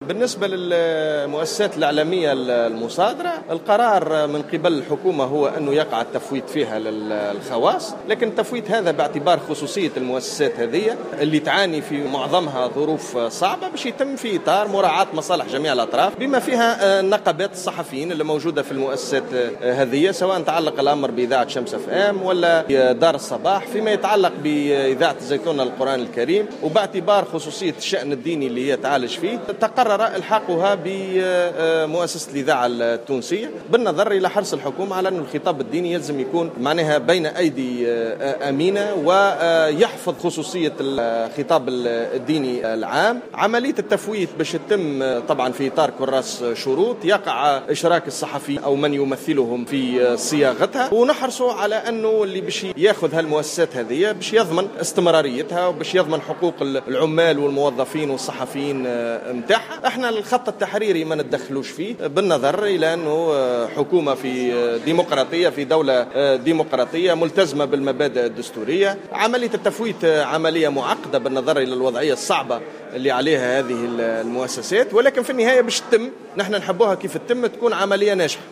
وجاء ذلك في رده على تساؤلات النواب في جلسة عامة مخصصة لتوجية أسئلة شفاهية لعدد من أعضاء الحكومة وللنظر في عدد من مشاريع القوانين.